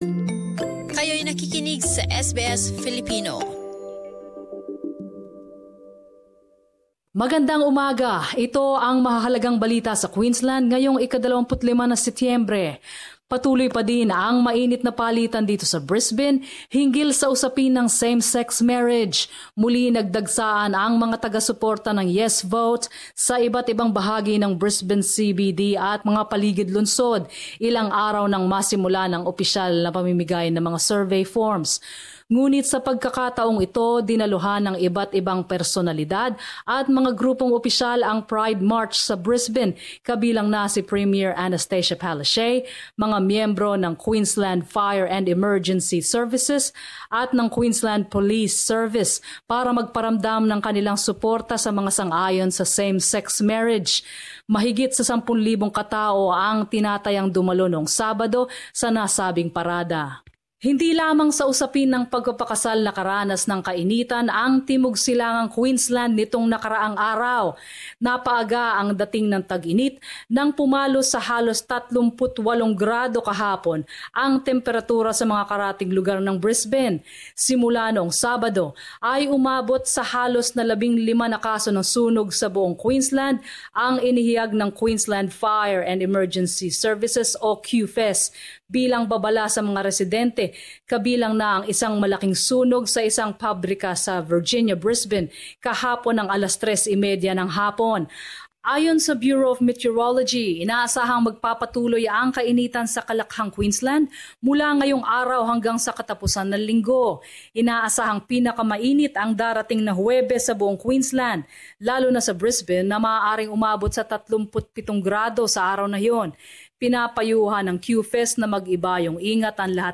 Queensland News.